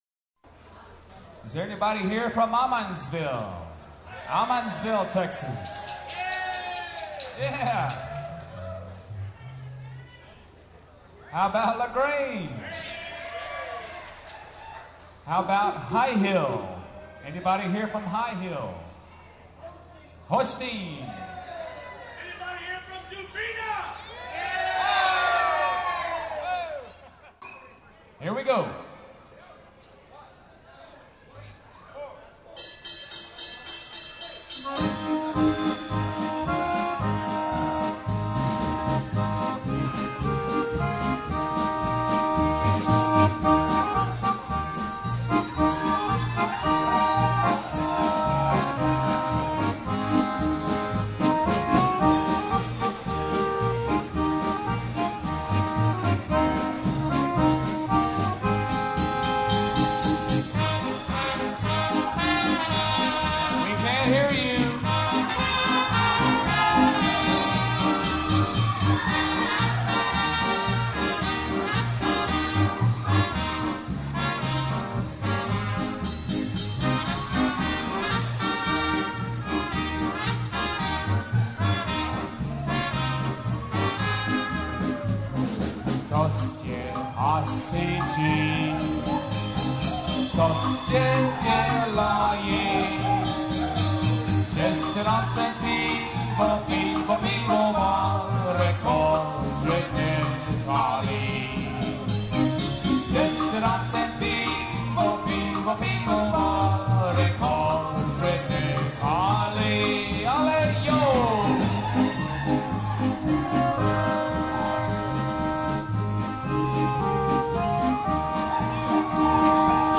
Polka
Waltz
Commentary